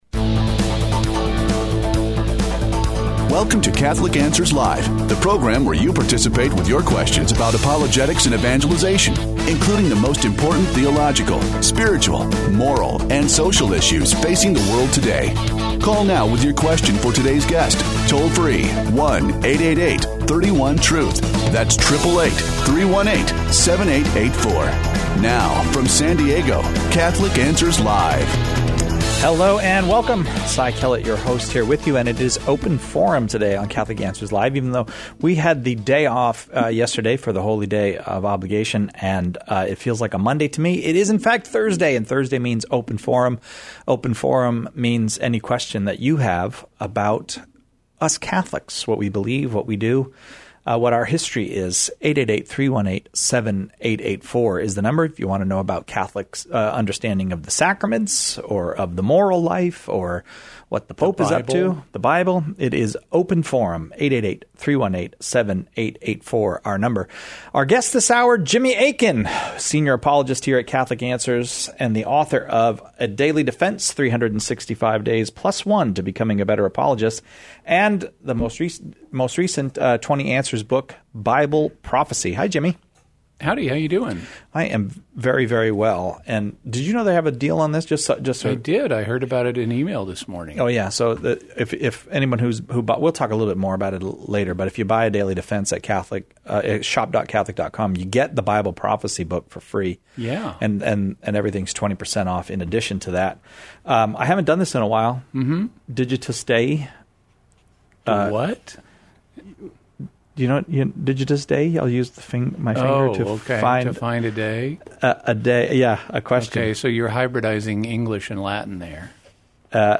The callers choose the topics during Open Forum, with questions on every aspect of Catholic life and faith, the moral life, and even philosophical topics.